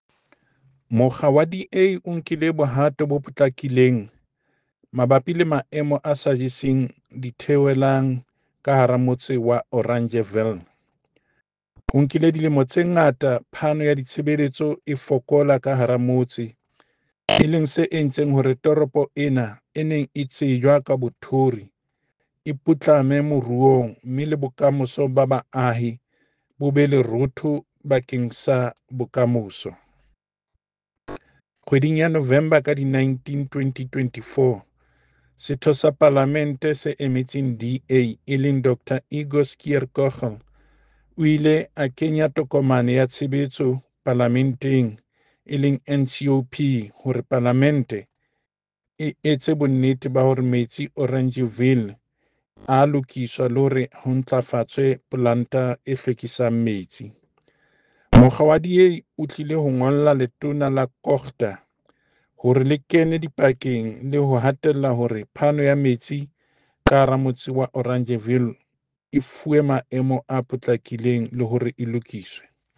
Sesotho soundbite by David Masoeu MPL with video here and link to motion here
Sewerage-crisis-in-Metsimaholo-SESOTHO.mp3